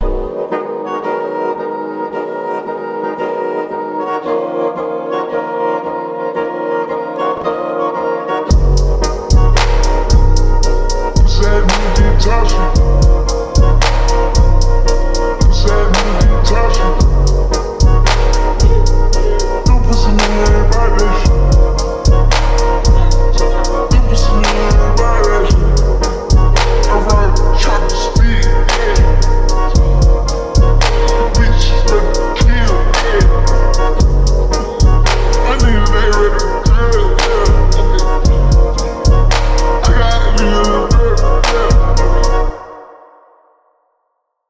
Enduro And Fourcross Race 💯.